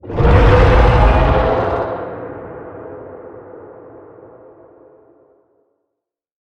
File:Sfx creature hiddencroc callout 02.ogg - Subnautica Wiki